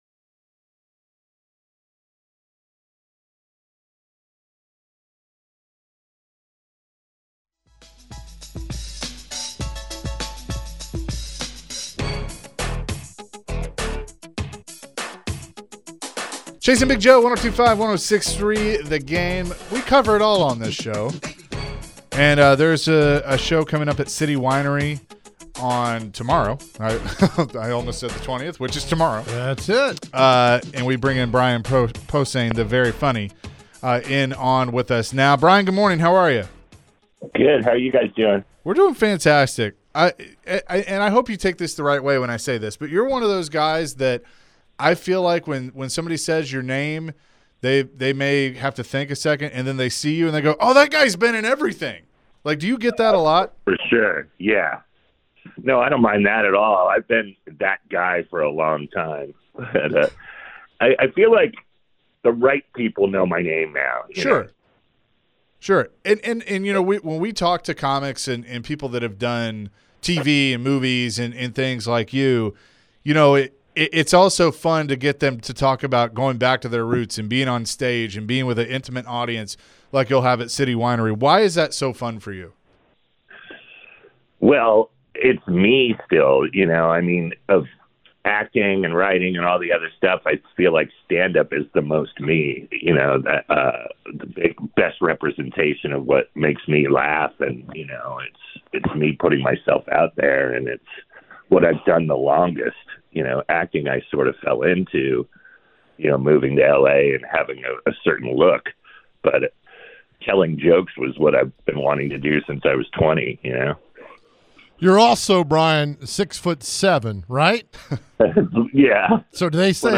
The guys were also joined by stand-up comedian Brain Posehn to discuss his journey. Brian is coming to Nashville to share a few laughs with some locals. Later in the conversation, Brian was asked about his WWE fandom.